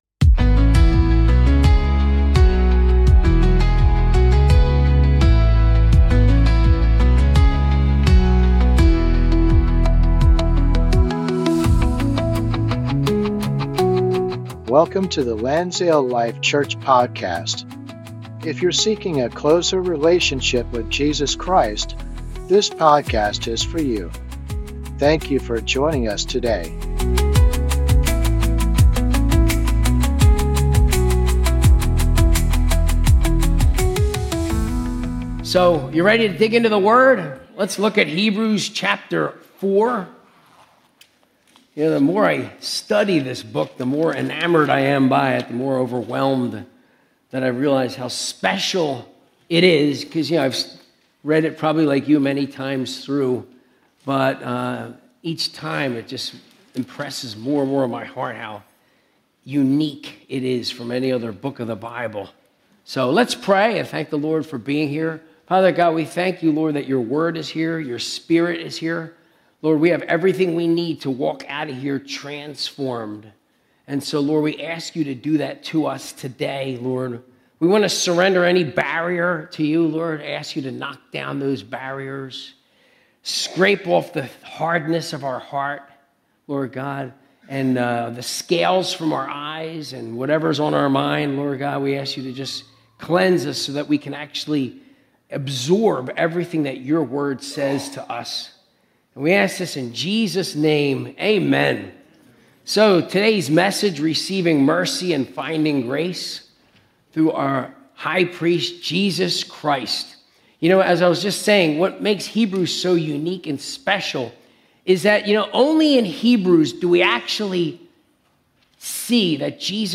Sunday Service - 2025-08-03